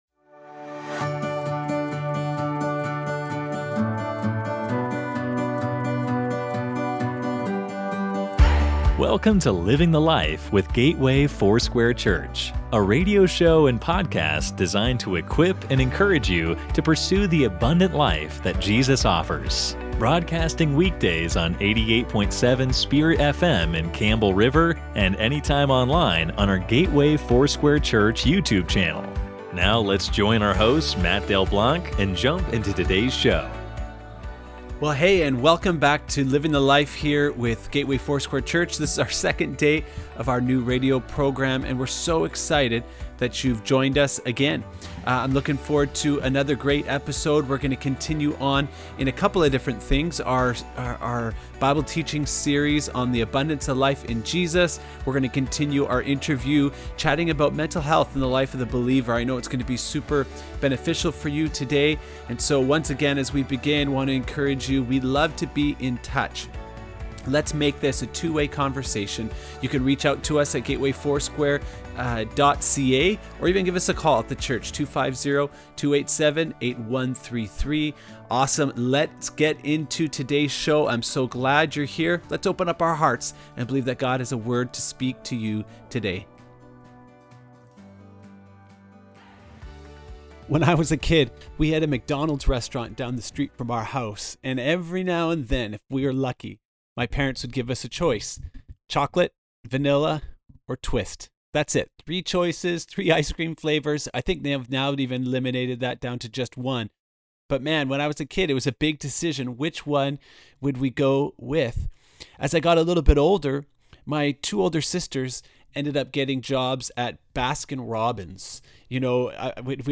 An encouraging teaching about life in Jesus and an equipping interview about mental health and the live of a believer.